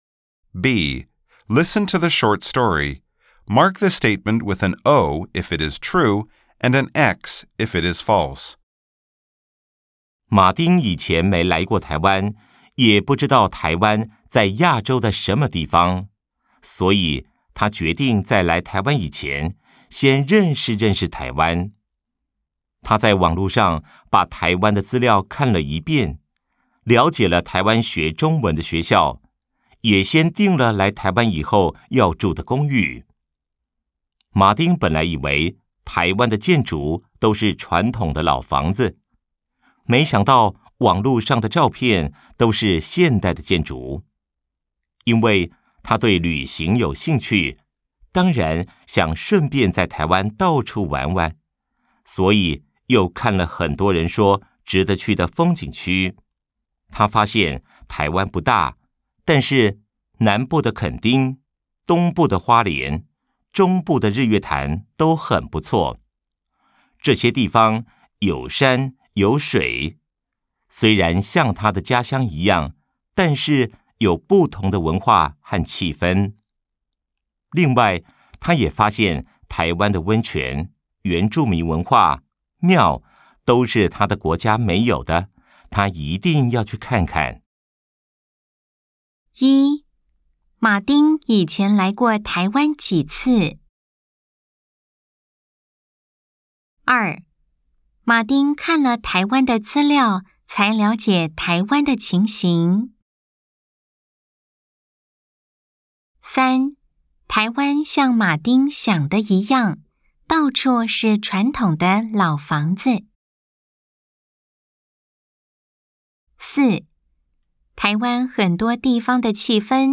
B. Nghe câu chuyện ngắn.